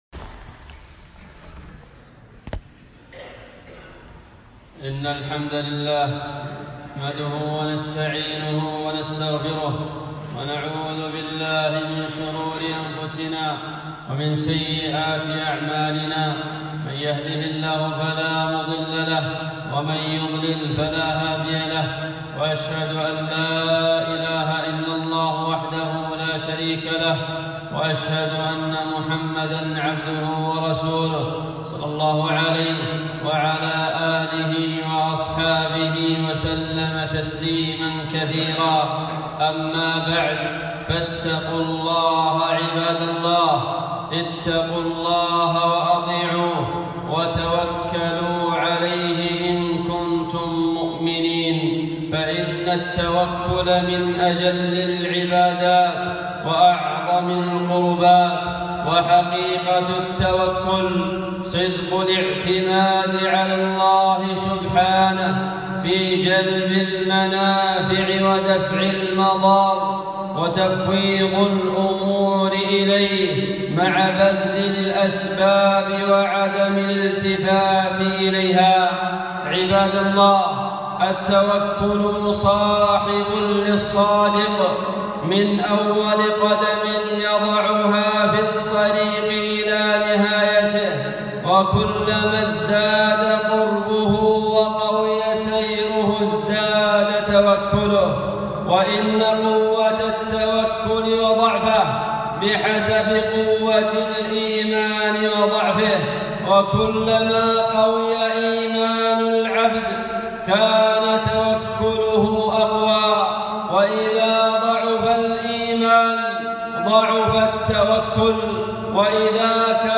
خطبة - التوكل على الله